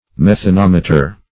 Search Result for " methanometer" : The Collaborative International Dictionary of English v.0.48: Methanometer \Meth`a*nom"e*ter\, n. [Methane + -meter.] An instrument, resembling a eudiometer, to detect the presence and amount of methane, as in coal mines.